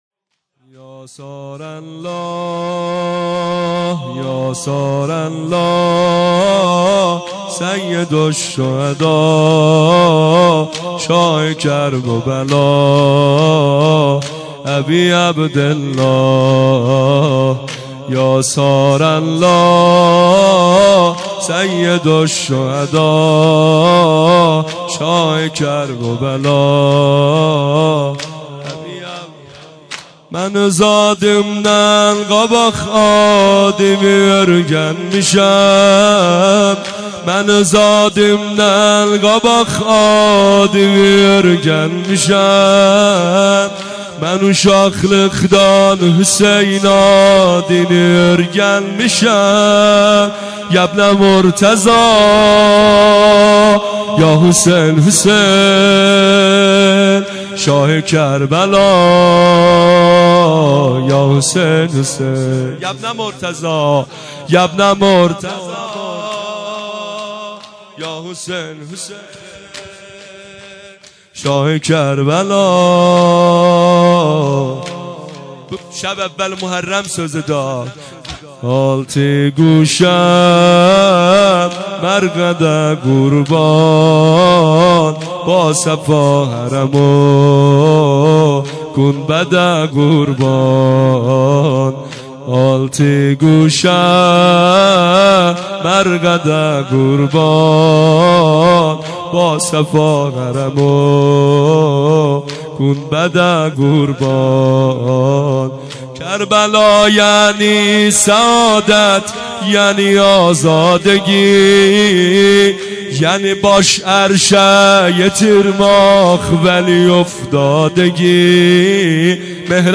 شور | یا ثارالله
سینه زنی شور محرم94 مداحی جدید